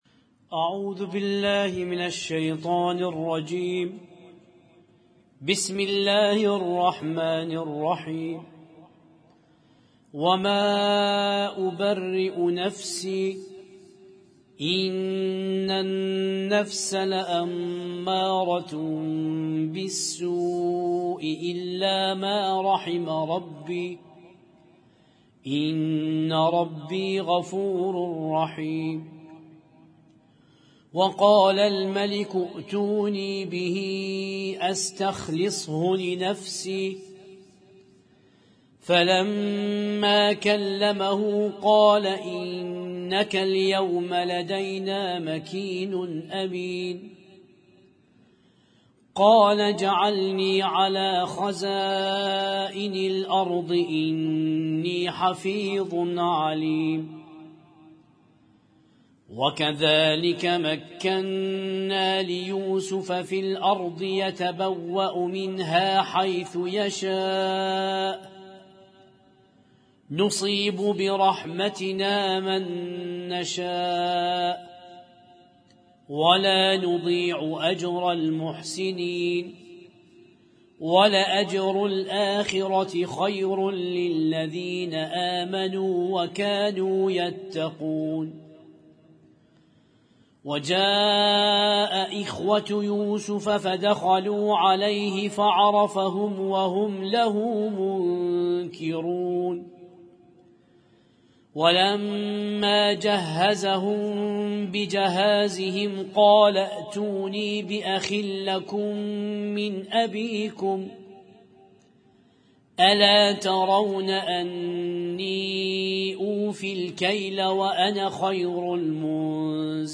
Husainyt Alnoor Rumaithiya Kuwait
القرآن الكريم